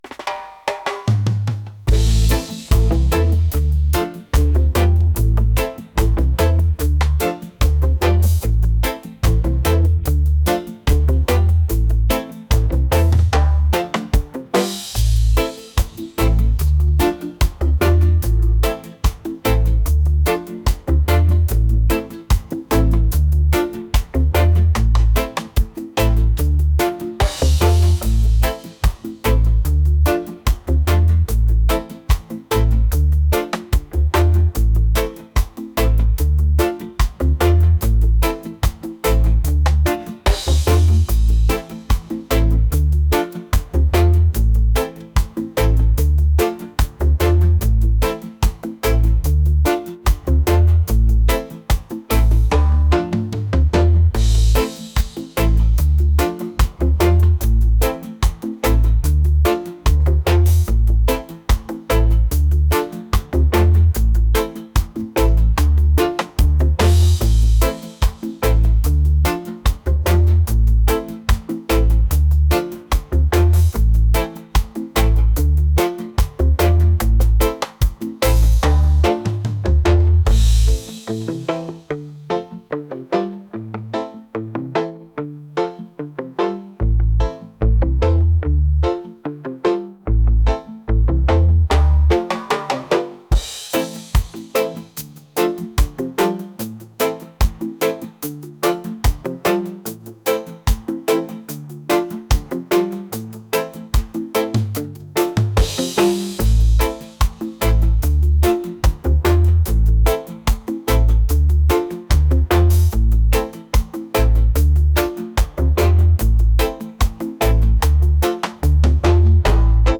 reggae | country | blues